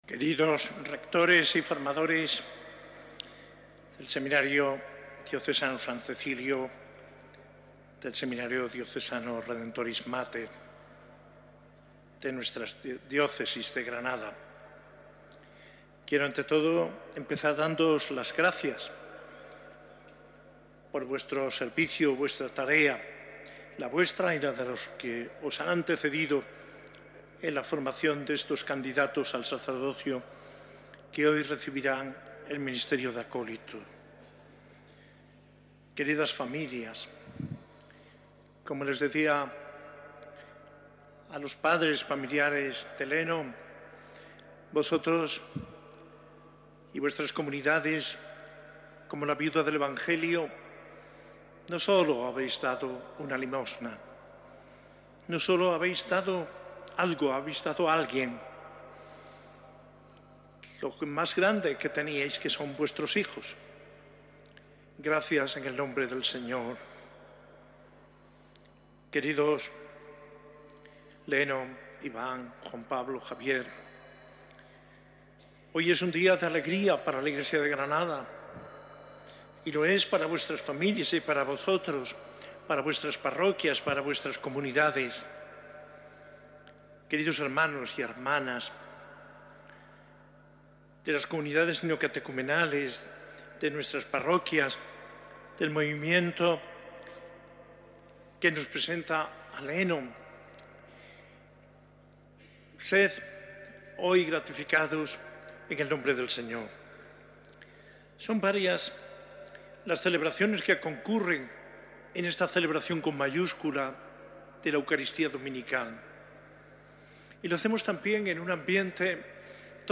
Homilía en la Eucaristía de institución de nuevos acólitos | ODISUR
Celebrada en la catedral el 10 de noviembre de 2024.